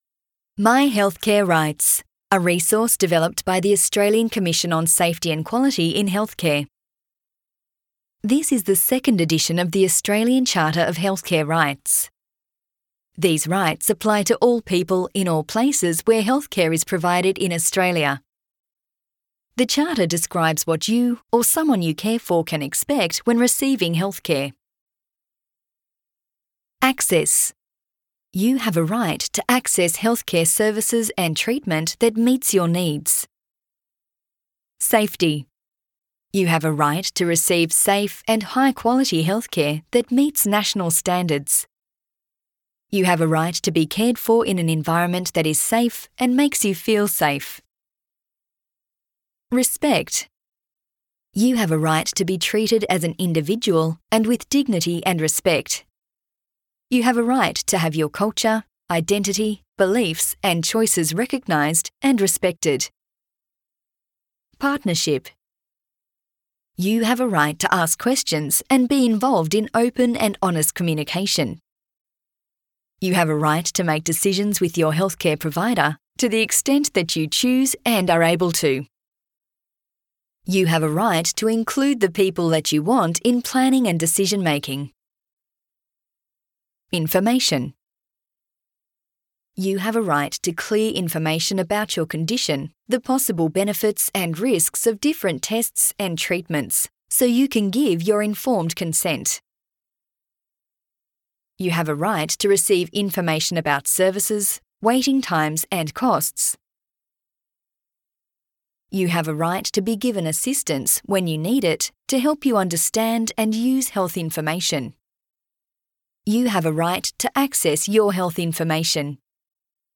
charter_of_healthcare_rights_-_audio_-_complete_charter_reading.mp3